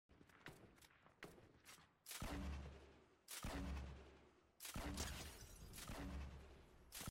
OG Fortnite Grenade Laucher sound effects free download
OG Fortnite - Grenade Laucher Spam, Got Them